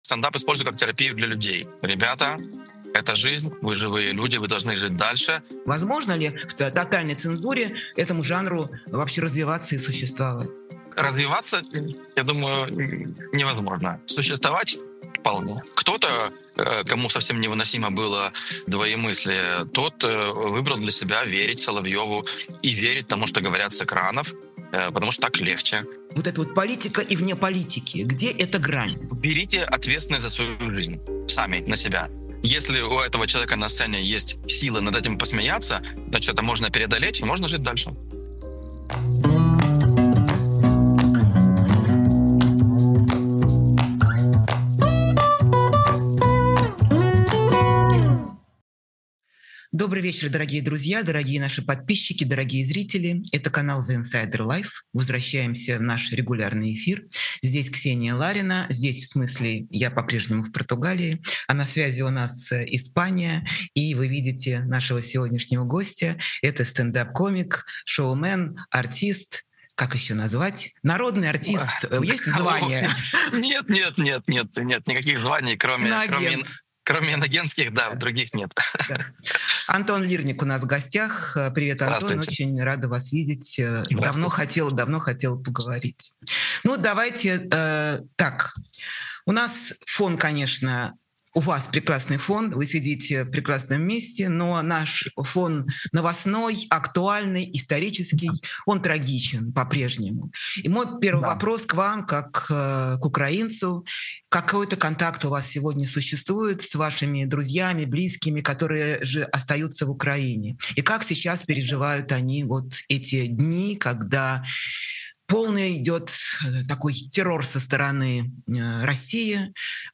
Эфир ведёт Ксения Ларина
Новый выпуск программы «Честно говоря» с Ксенией Лариной. Гость — стендап-комик Антон Лирник.